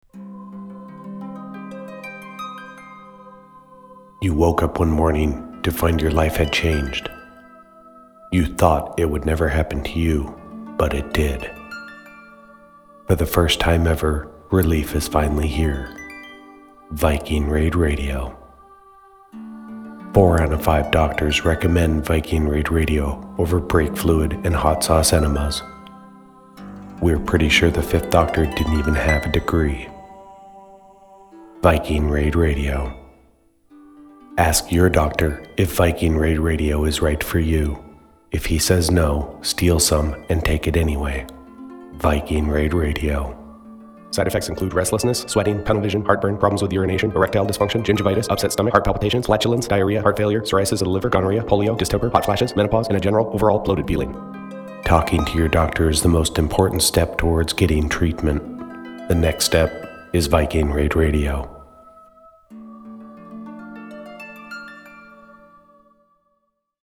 I did three promos based on pharmaceutical ads.